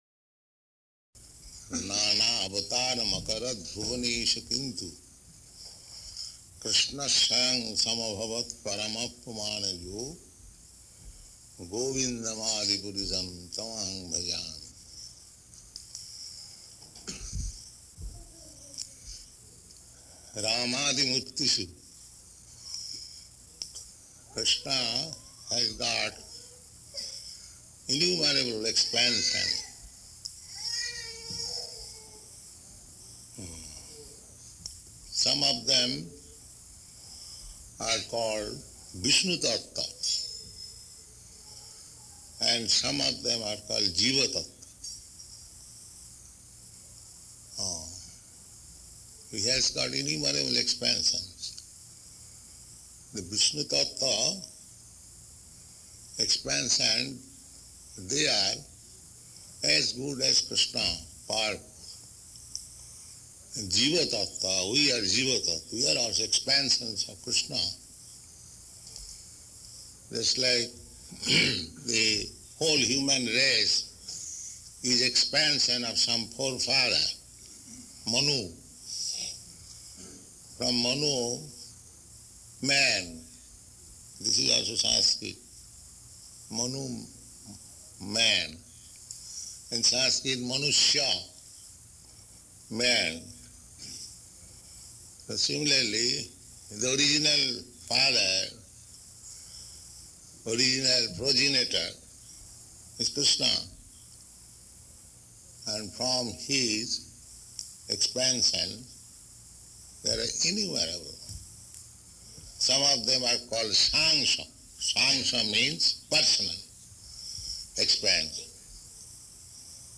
Location: Los Angeles
[Distorted]